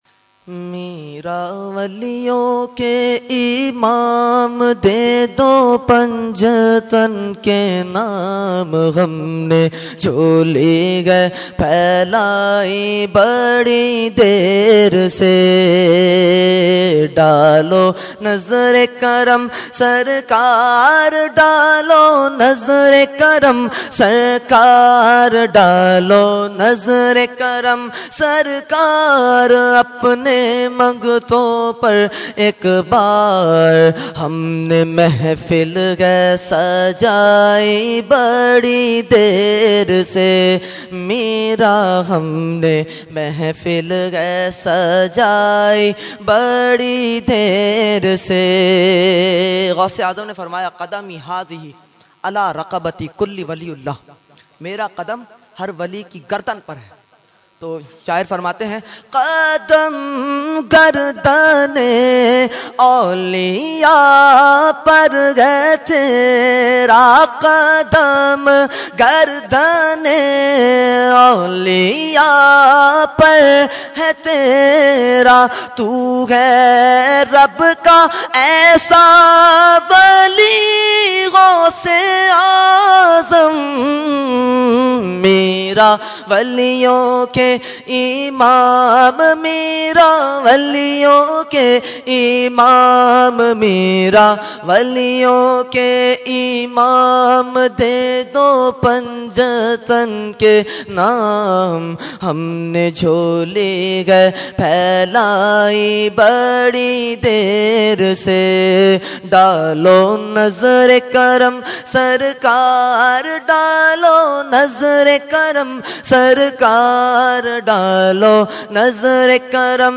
Category : Manqabat | Language : UrduEvent : 11veen Shareef 2014